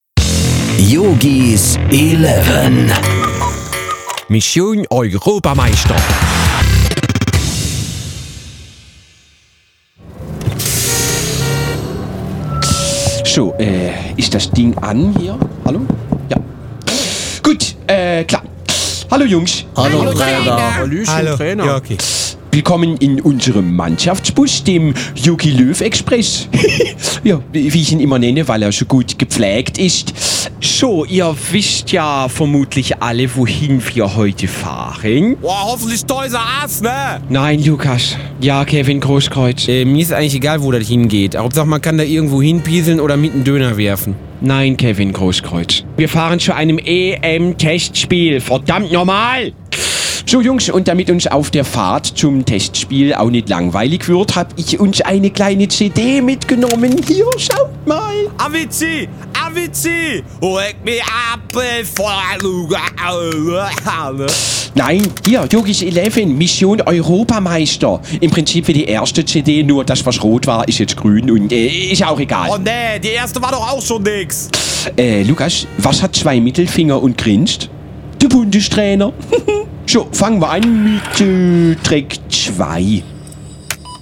Schlagworte EM 2016 • Europameister • Fußball • Hörbuch; Humor/Comedy • Jogi Löw • Jogis Eleven • Radio-Comedy • Weltmeister